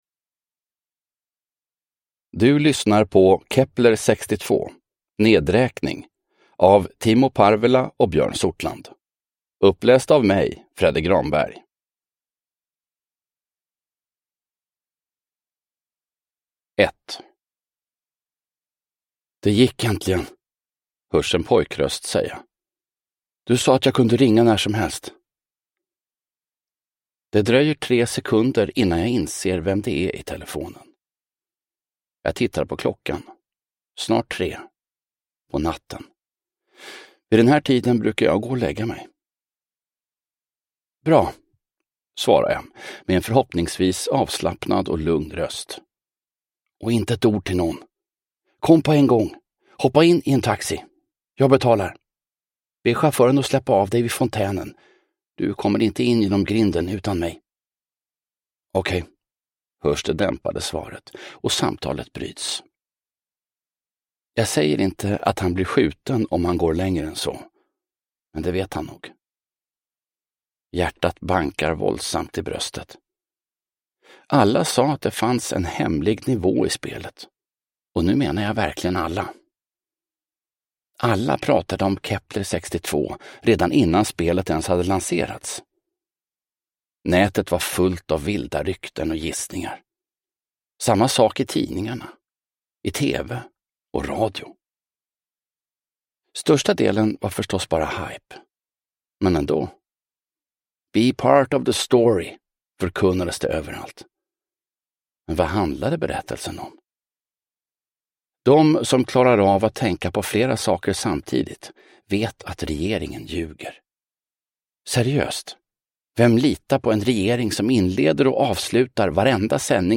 Nedräkning – Ljudbok – Laddas ner